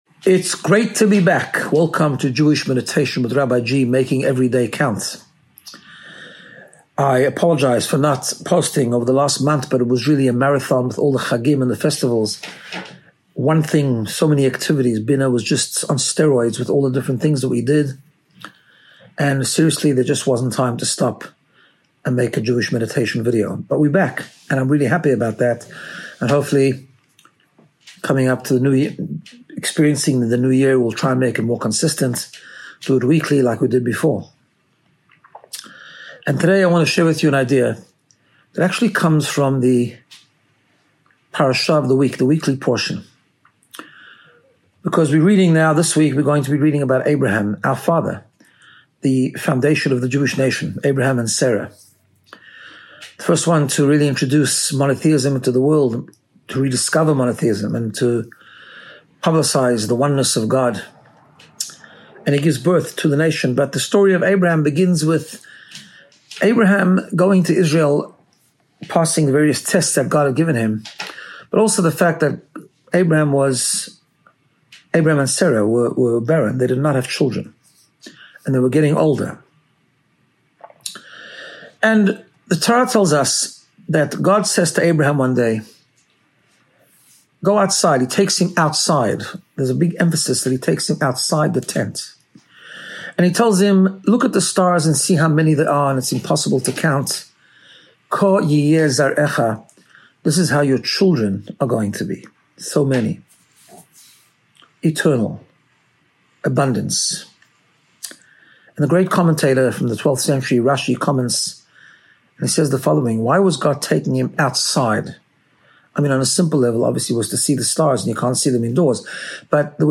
Jewish Meditation